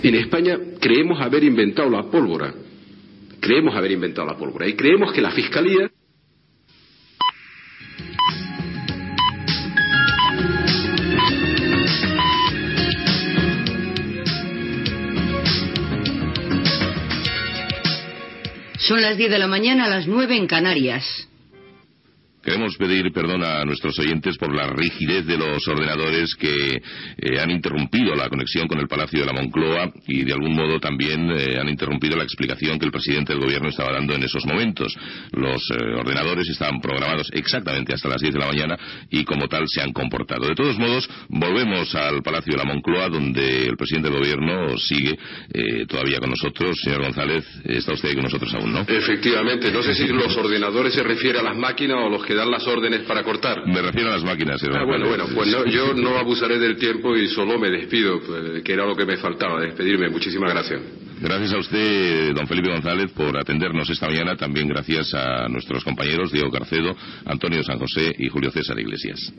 L'automatisme dels senyals horaris talla una entrevista al president del Govern espanyol Felipe González. Hora, disculpes per la interrupció de la connexió amb el Palau de la Moncloa i comiat del president del govern i de l'entrevista
Info-entreteniment